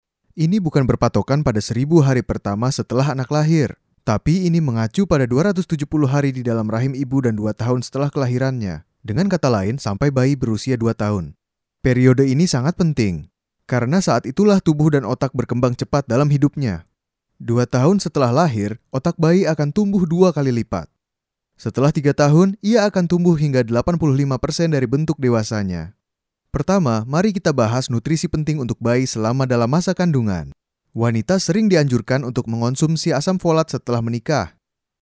特点：轻快活力 大气浑厚 稳重磁性 激情力度 成熟厚重
印尼语男女样音